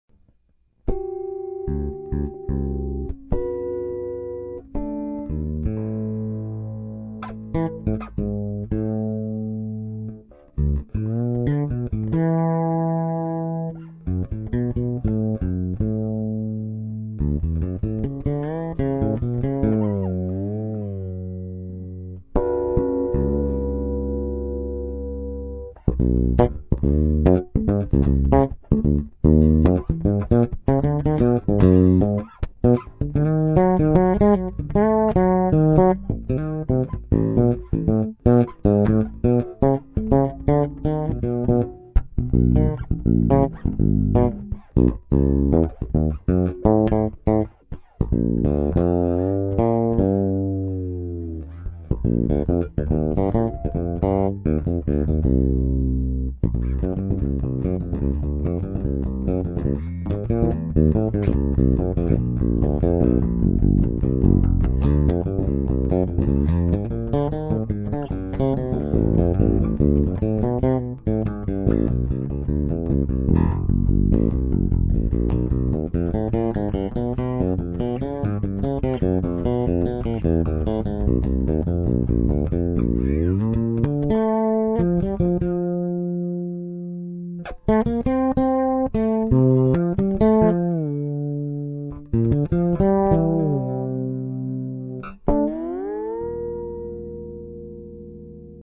Fender Jazz factory fretless w/ HGT Epoxied rosewood fretboard